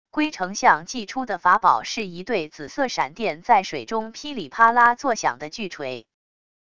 龟丞相祭出的法宝是一对紫色闪电在水中噼里啪啦作响的巨锤wav音频